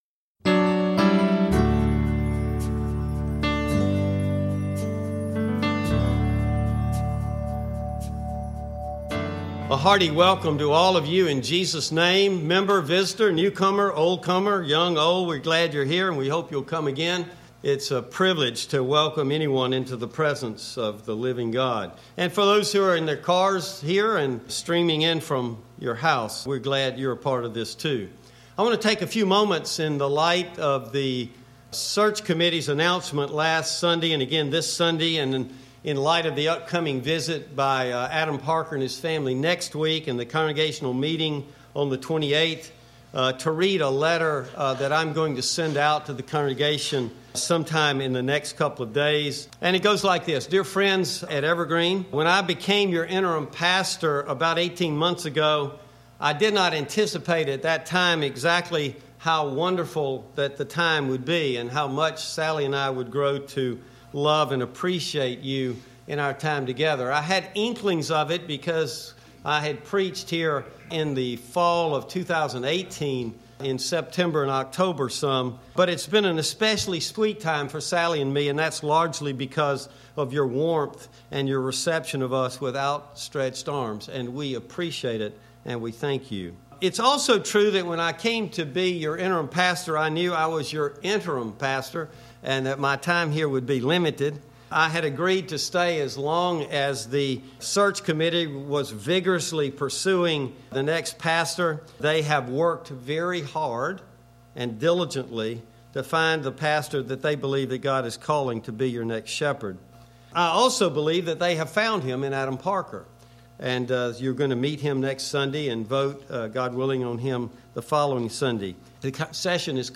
Click for full service video and sermon audio.